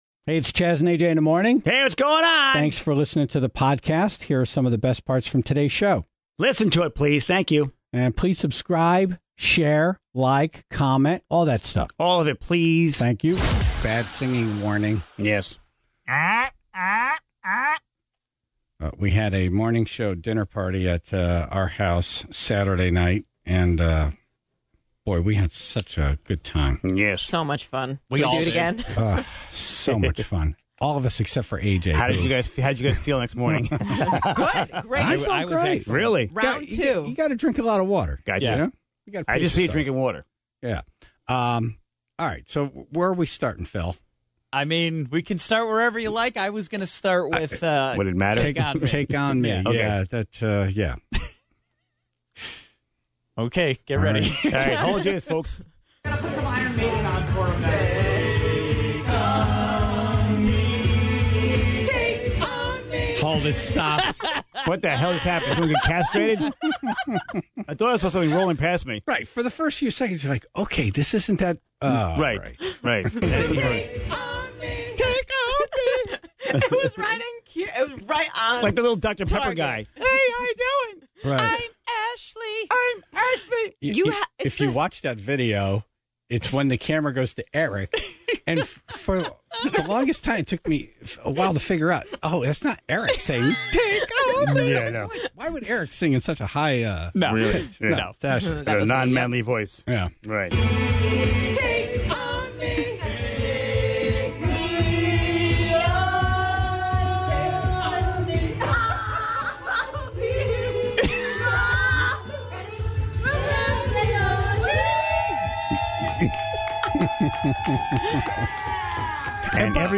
Senator Blumenthal in studio; first, he gets quizzed on millenial terms, then the Senator talked about wanting to grow up to be a rancher (29:13)